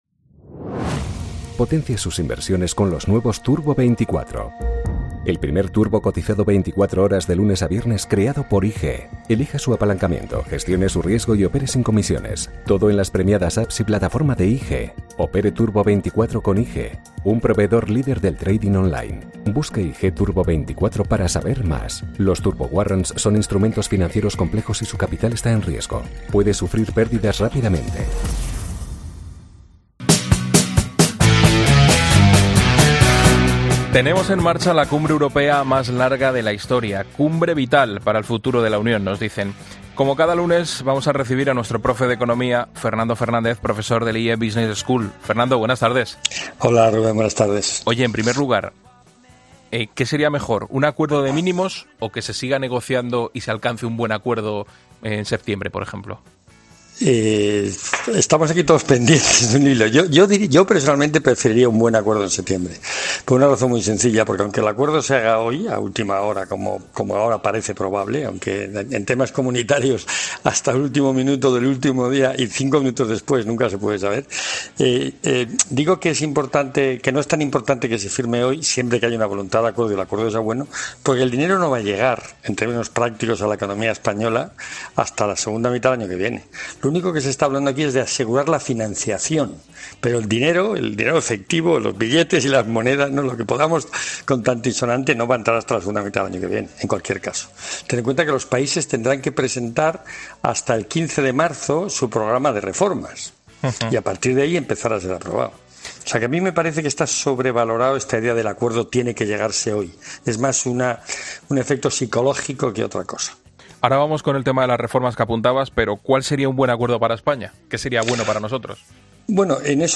Como cada lunes vamos recibir a nuestro profe de economia